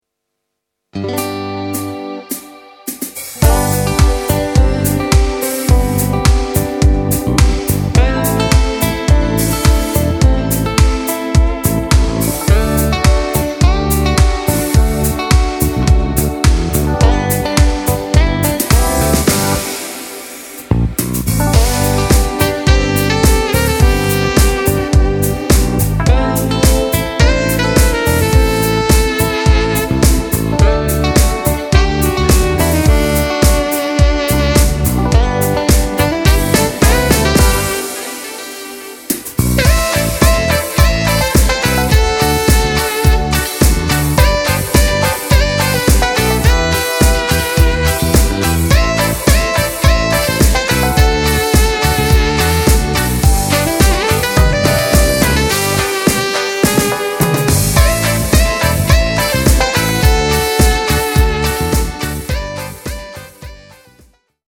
Nastrojowy utwór
podkład dla wokalistów